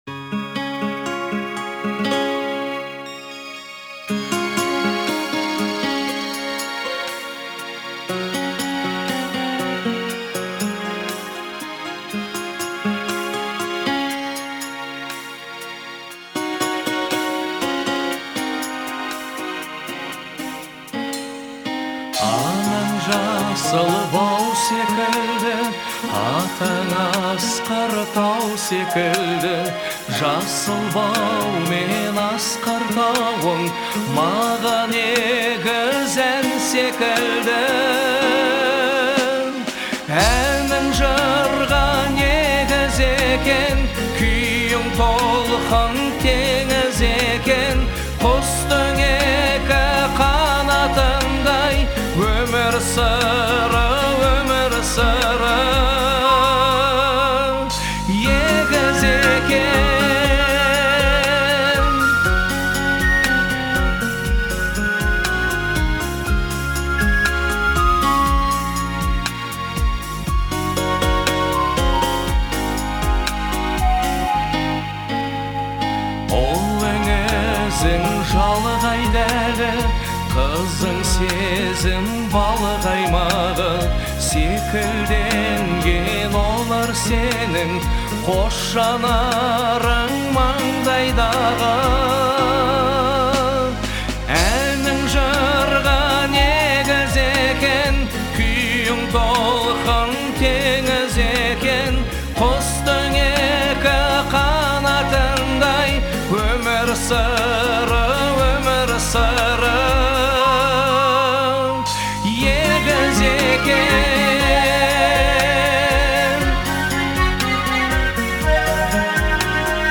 который сочетает в себе элементы поп и фолка.